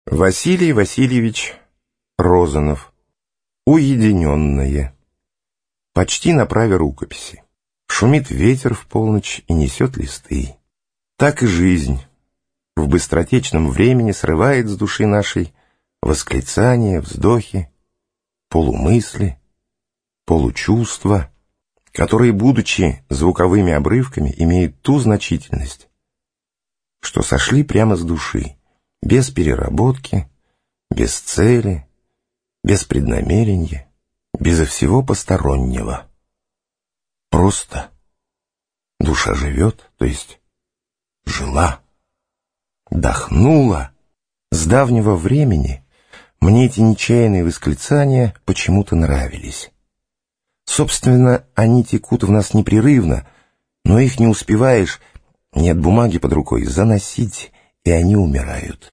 Аудиокнига Уединенное; Опавшие листья. Короб первый.